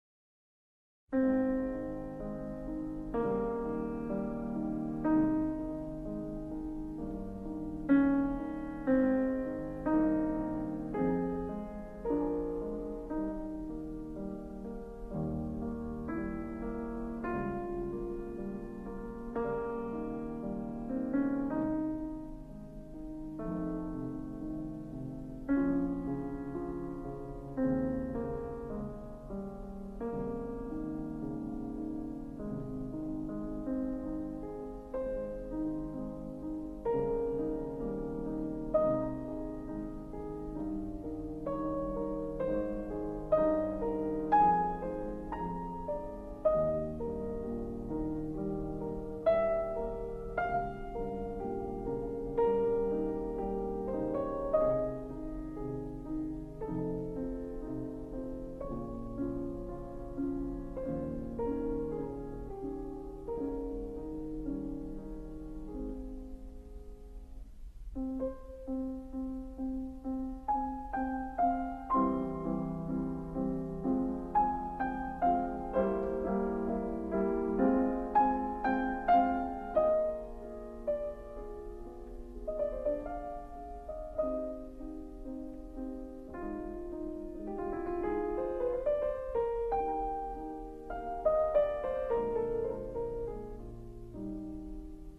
* Ludwig van Beethoven – Piano Sonata No.8 in C minor, Op.13 (Pathetique)